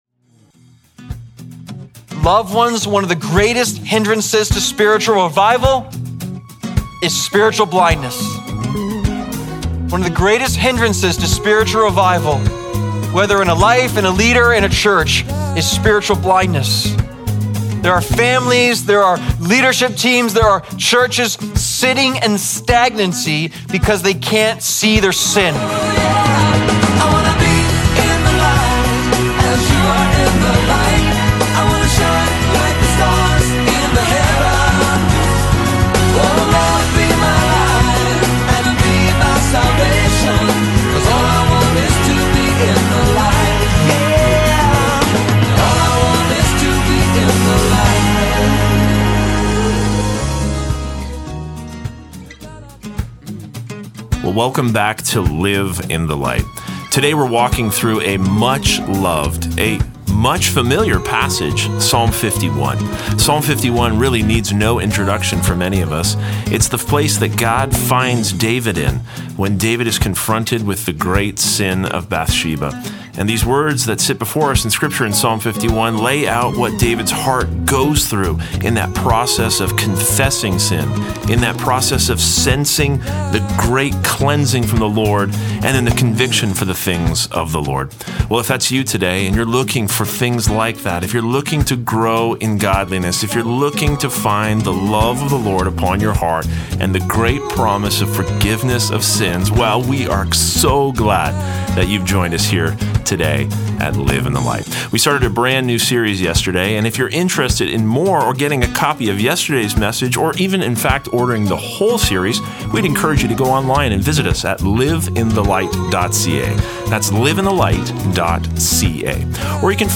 Daily Broadcast